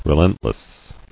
[re·lent·less]